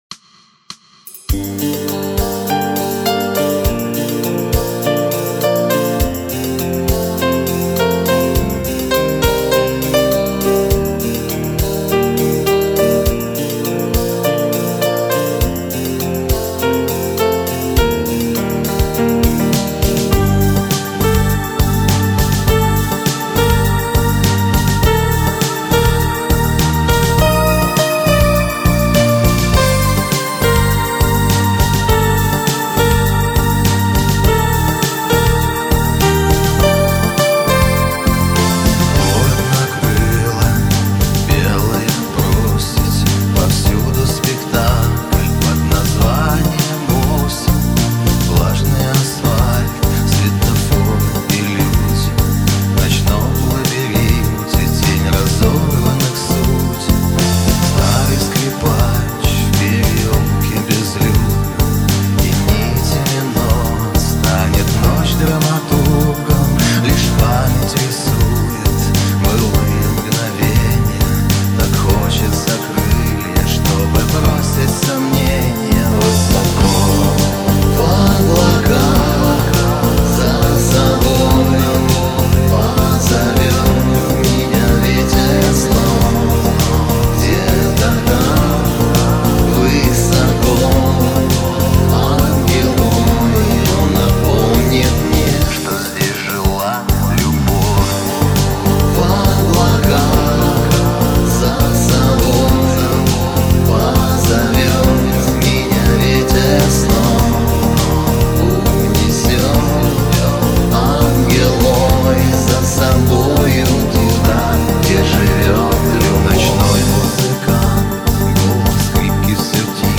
Ну что поделать - грустная песня.